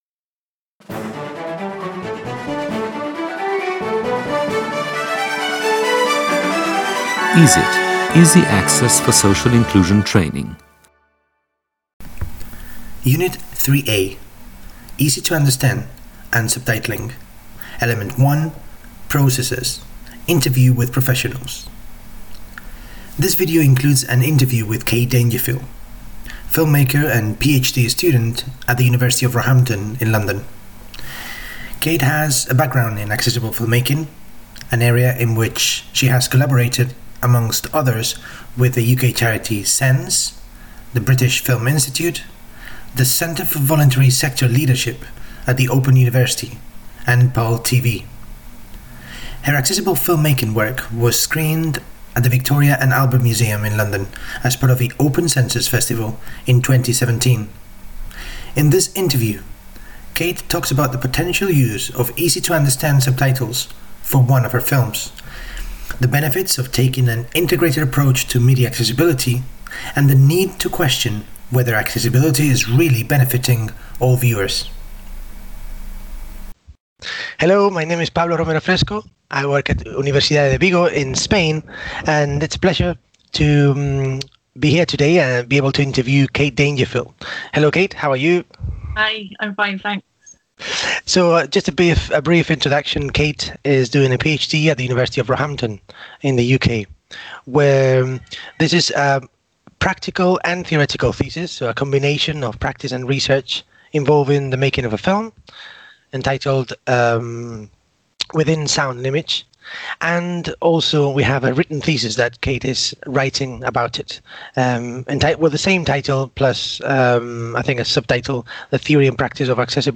3A.1.10. Interview with professionals 1
U3A.E1._Interview_with_professionals_1_UVIGO_AUDIO.mp3